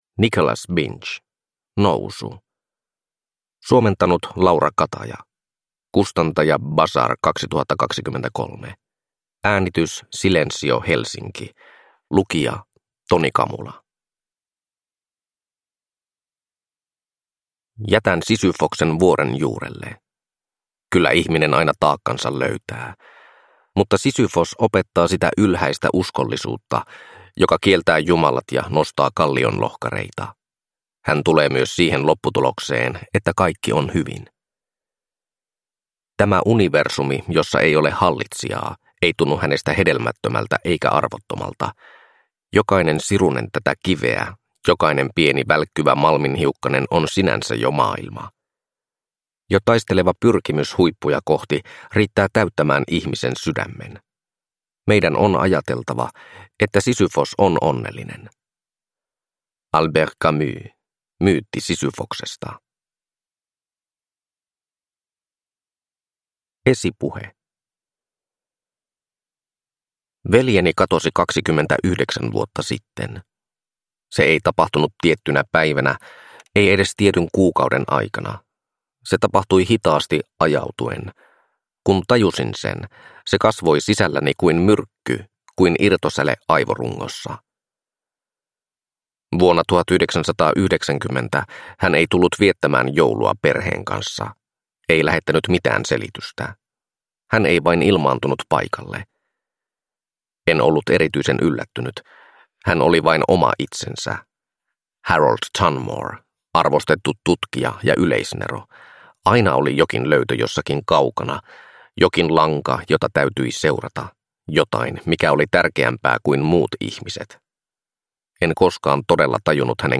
Nousu – Ljudbok